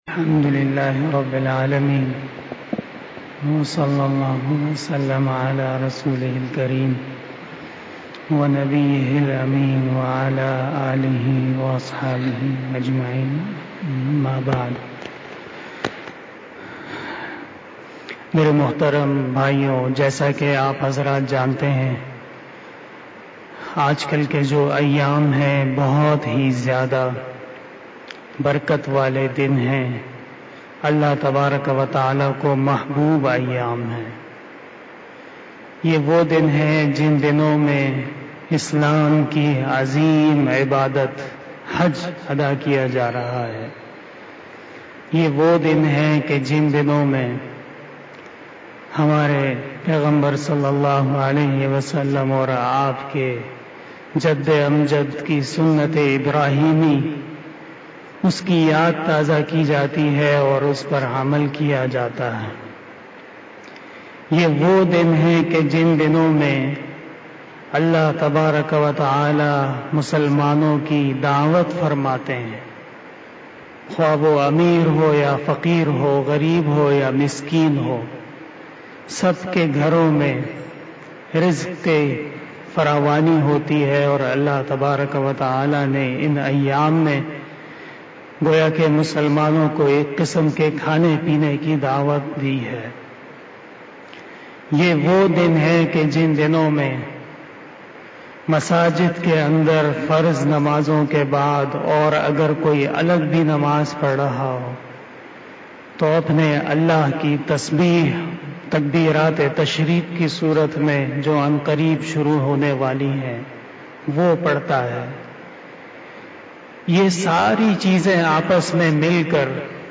بیان شب جمعۃ المبارک 09 ذی الحج 1441ھ بمطابق30 جولائی 2020ء